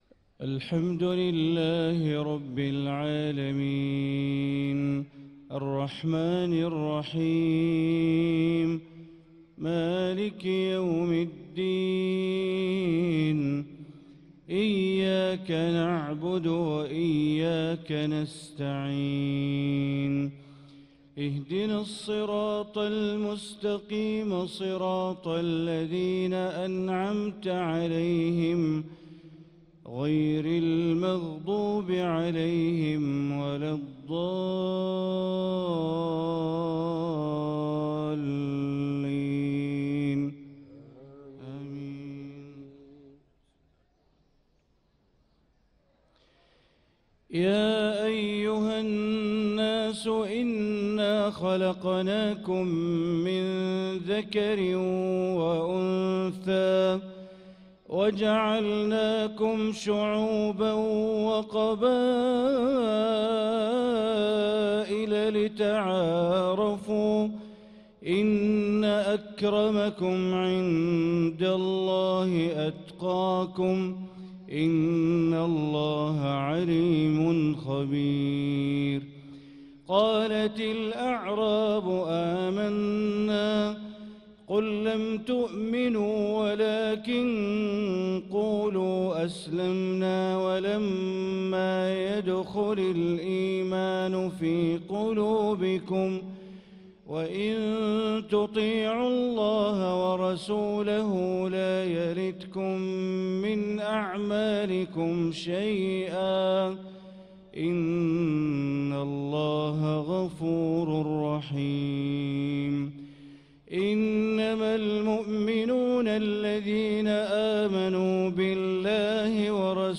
صلاة المغرب للقارئ بندر بليلة 3 شوال 1445 هـ
تِلَاوَات الْحَرَمَيْن .